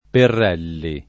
Perrelli [ perr $ lli ]